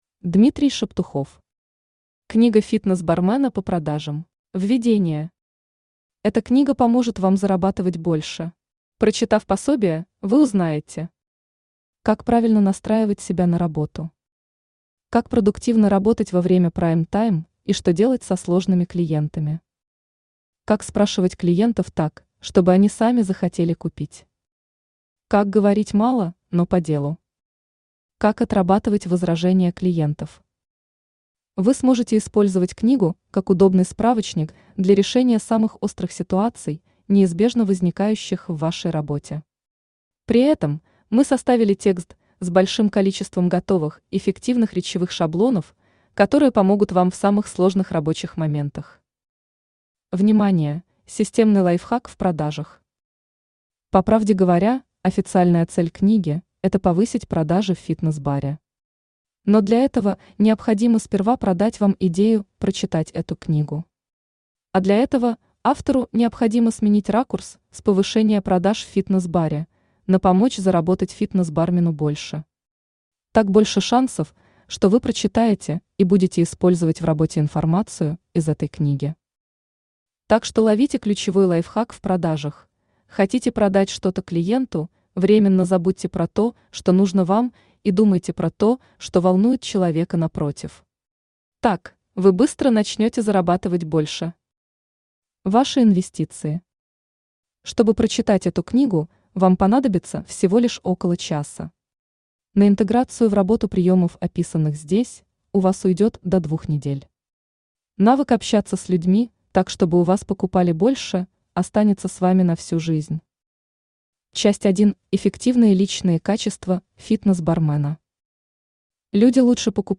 Aудиокнига Книга фитнес бармена по продажам Автор Дмитрий Шептухов Читает аудиокнигу Авточтец ЛитРес.